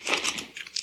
equip_diamond1.ogg